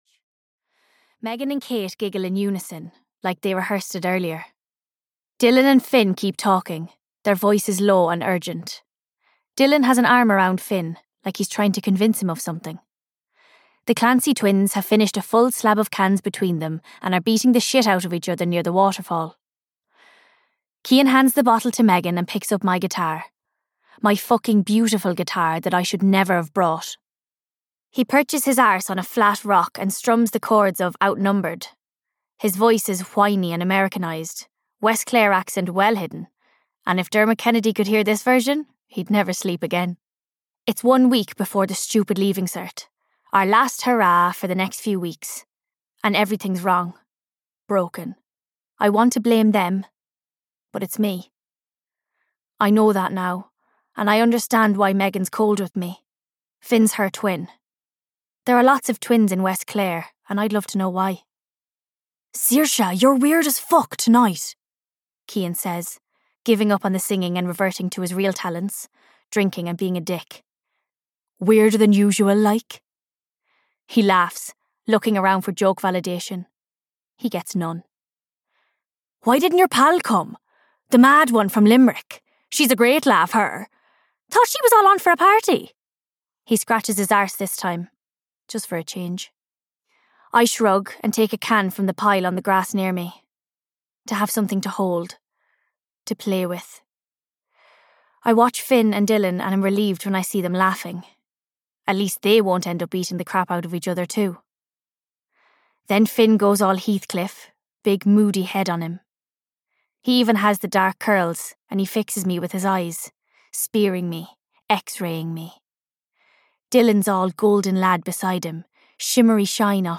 Things I Know (EN) audiokniha
Ukázka z knihy